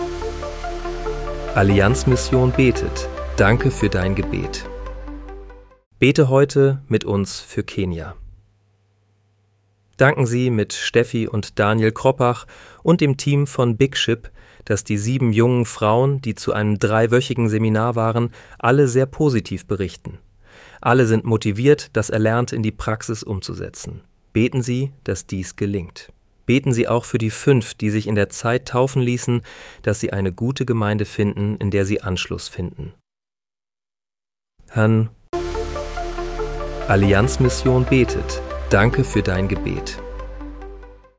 Bete am 27. Februar 2026 mit uns für Kenia. (KI-generiert mit der